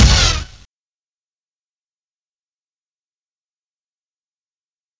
1up.wav